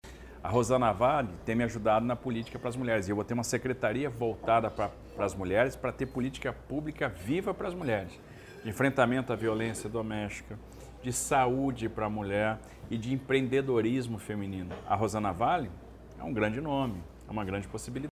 Em entrevista ao canal Talk Churras, o ex-ministro da Infraestrutura e candidato ao governo do Estado, Tarcísio de Freitas (Republicanos), antecipou alguns nomes que podem fazer parte do seu eventual secretariado, caso vença as eleições no dia 30 de outubro.
Audio-Tarcisio.mp3